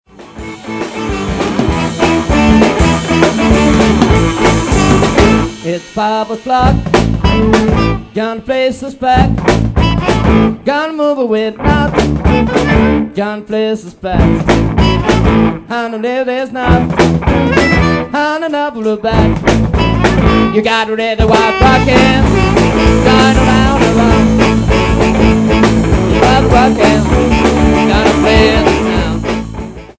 Estratti di brani registrati in sala prove